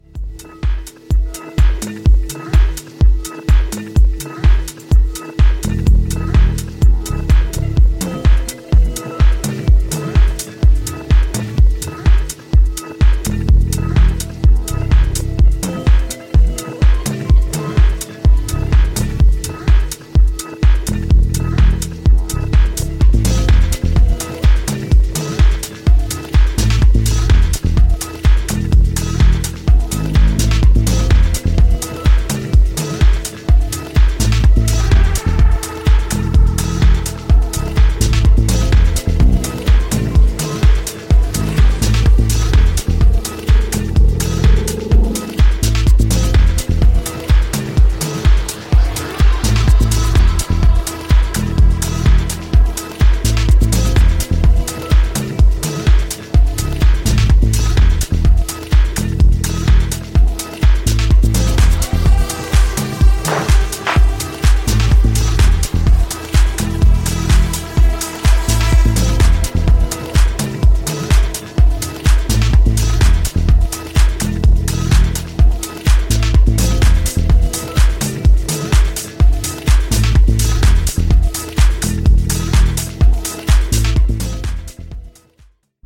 the most slick and up tempo of the lot, with rubbery